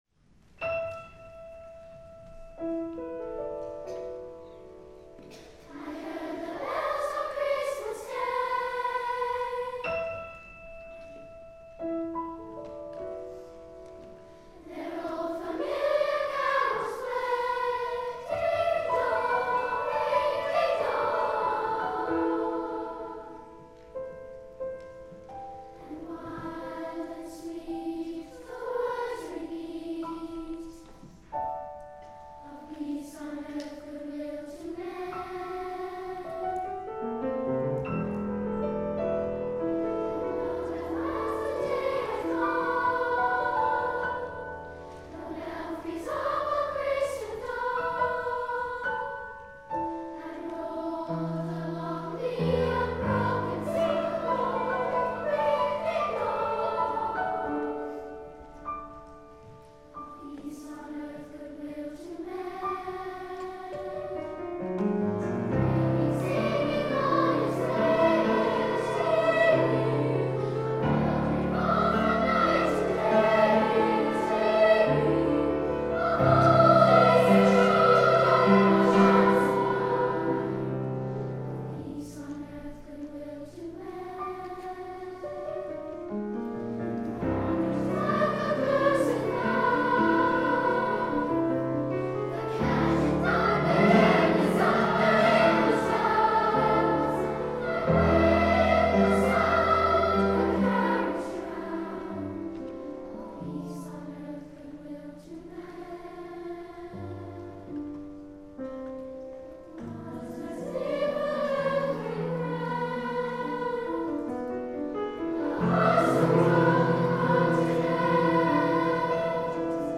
For Treble Chorus, Piano and Celesta (opt.)
• INSTRUMENTATION: ssa, pno, celesta
for treble chorus, solo and piano (with optional celesta).